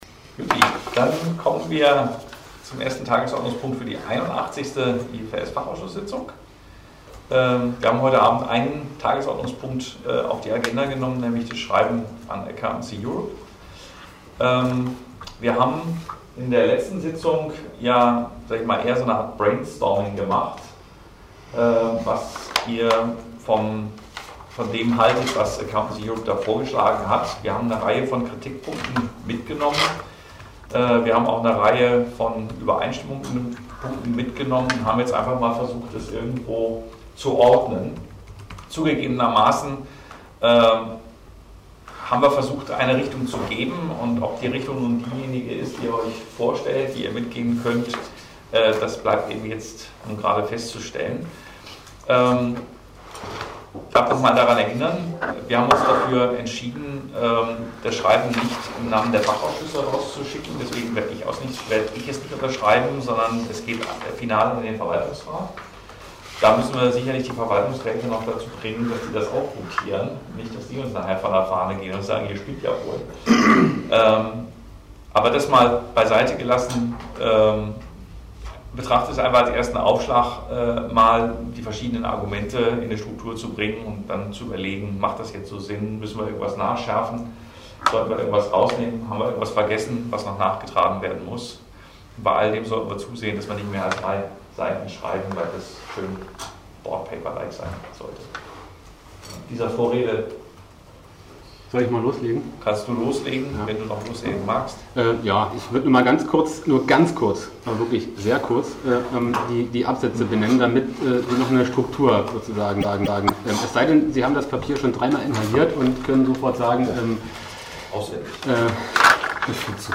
81. Sitzung IFRS-FA • DRSC Website